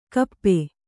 ♪ kappe